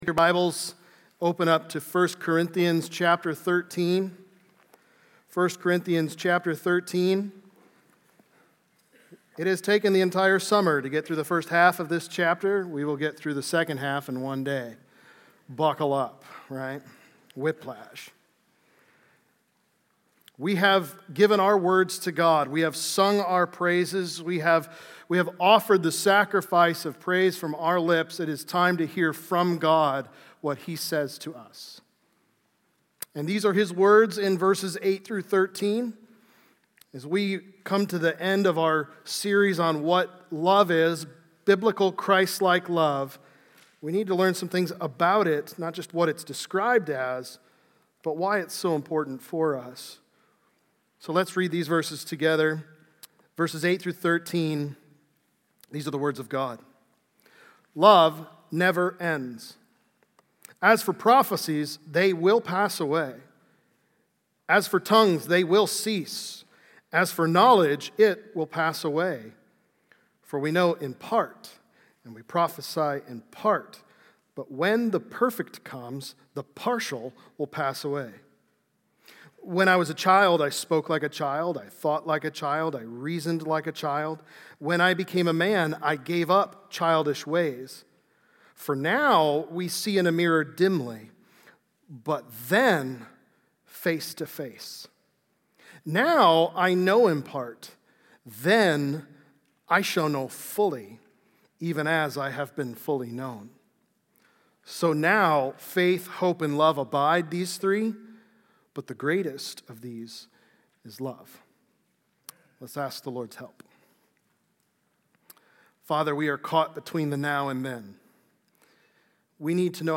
Love Never Ends | Baptist Church in Jamestown, Ohio, dedicated to a spirit of unity, prayer, and spiritual growth